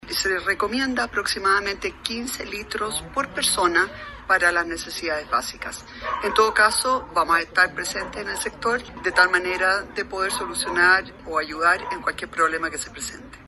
La alcaldesa de Providencia, Evelyn Matthei, destacó que todo el sector de Bellevista se verá afectado, por lo que recomendó juntar hasta 15 litros de este recurso por persona.